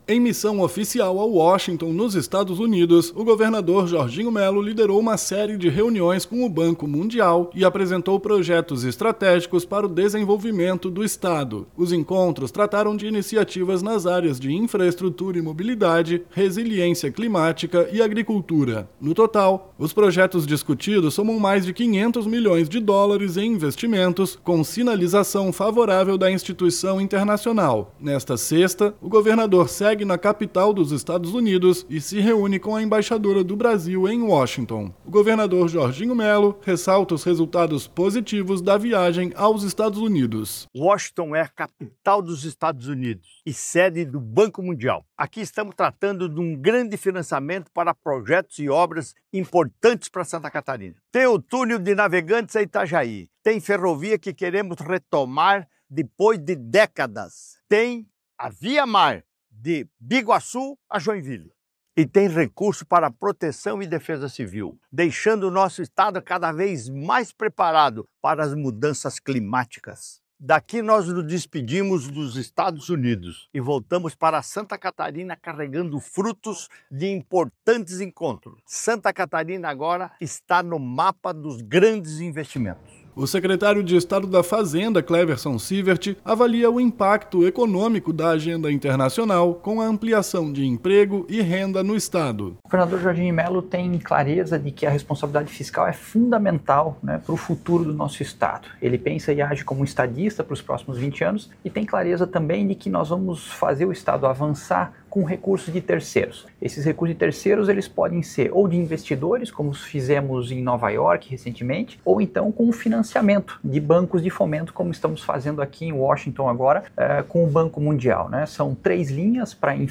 O governador Jorginho Mello ressalta os resultados positivos da viagem aos Estados Unidos:
O secretário da Fazenda, Cleverson Siewert, avalia o impacto econômico da agenda internacional com a ampliação de emprego e renda no estado:
O secretário executivo de Articulação Internacional e Projetos Estratégicos, Paulo Bornhausen, destaca os avanços do projeto: